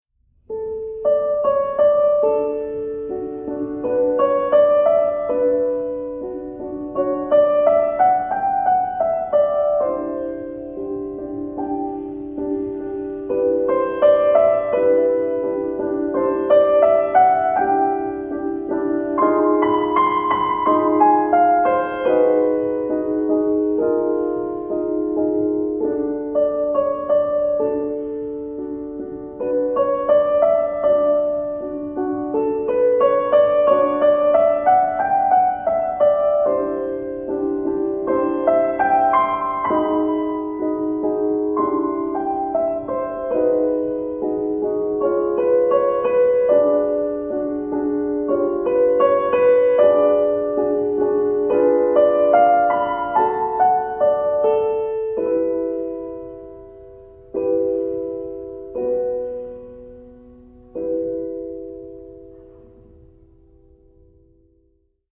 фортепианная версия
Рубрика Инструментальная музыка Метки: , , , , ,